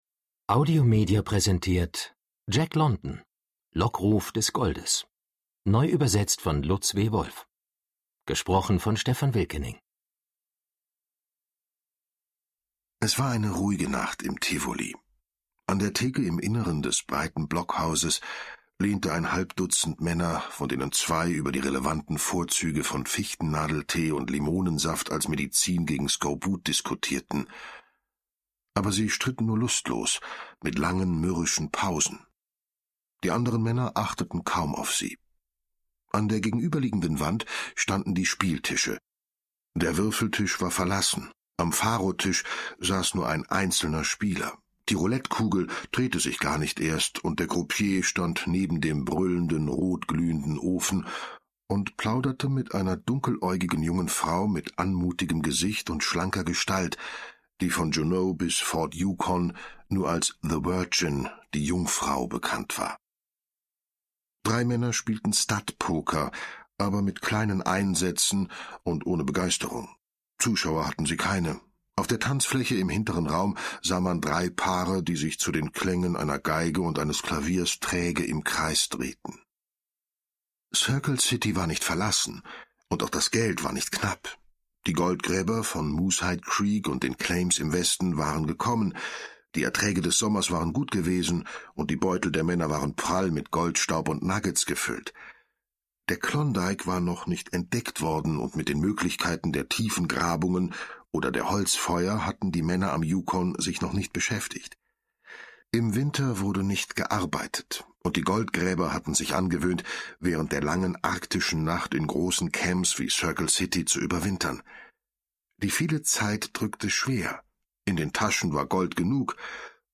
Hörbuch; Literaturlesung